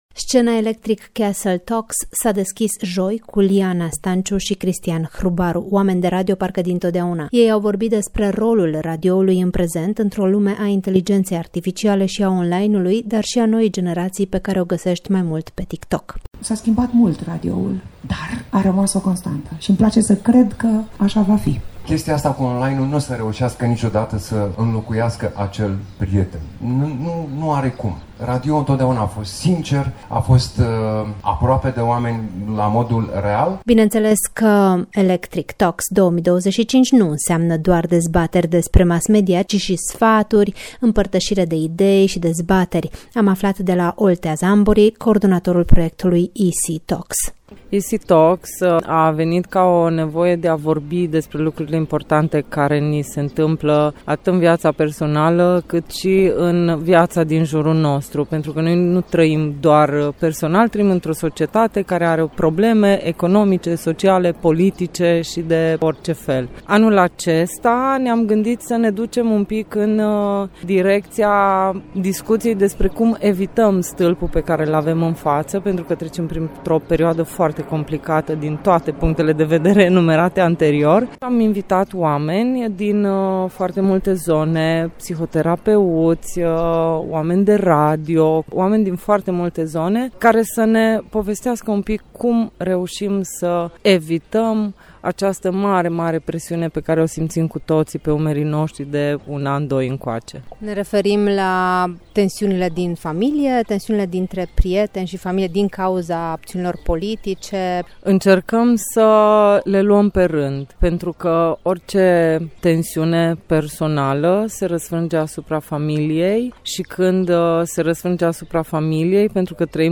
reportaj-electric.mp3